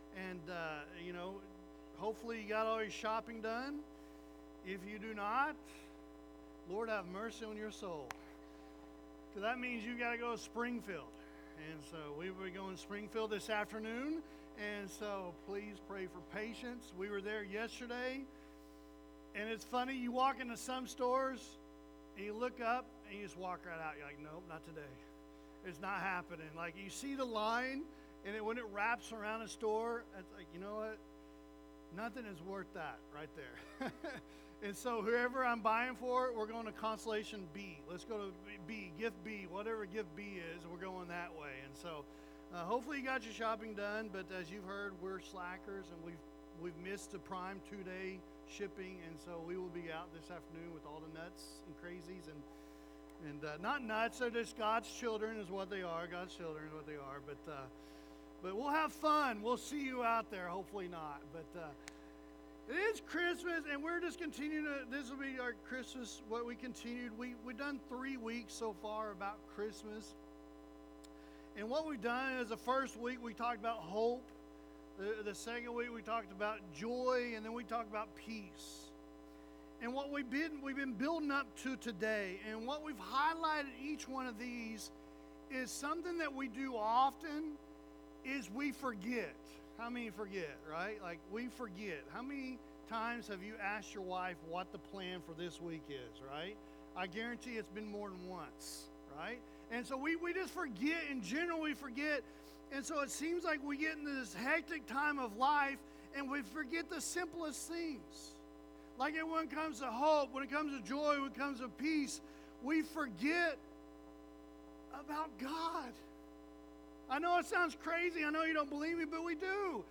Sermons | Brighton Assembly